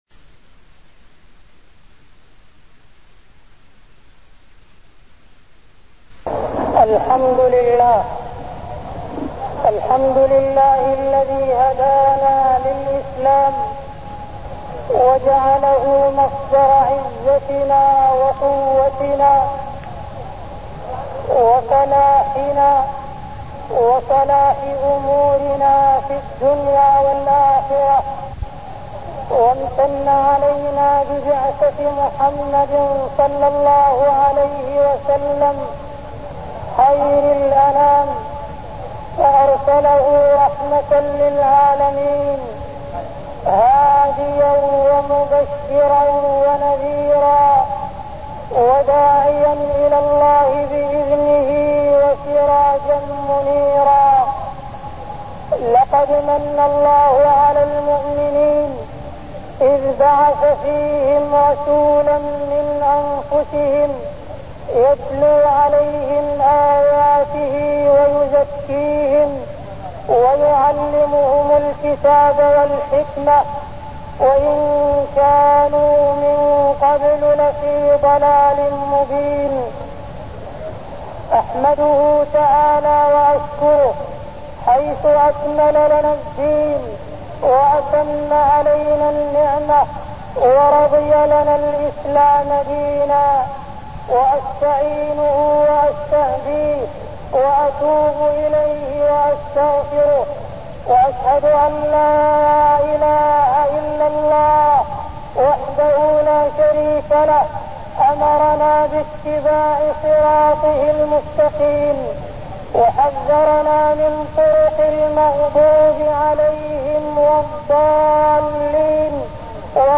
المكان: المسجد الحرام الشيخ: معالي الشيخ أ.د. عبدالرحمن بن عبدالعزيز السديس معالي الشيخ أ.د. عبدالرحمن بن عبدالعزيز السديس بعثة النبي صلى الله عليه وسلم